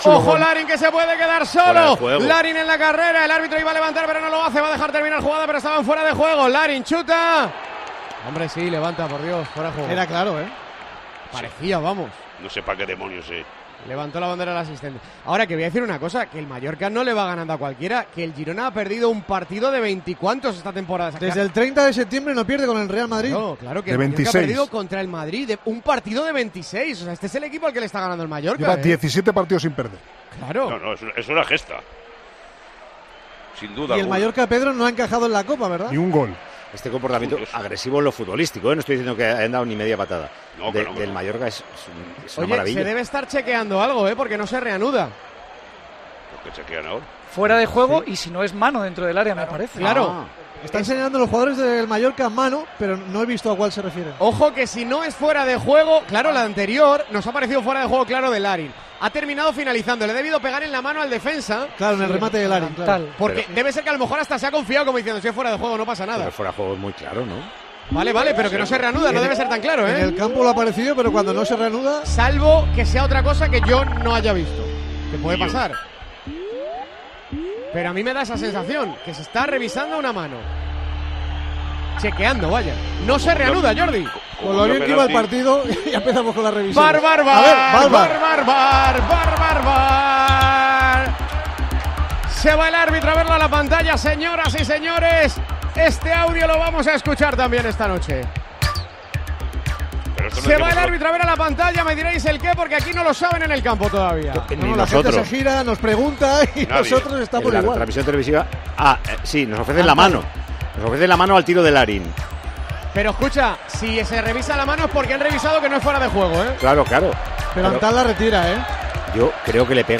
Así vivimos en Tiempo de Juego la retransmisión del Mallorca - Girona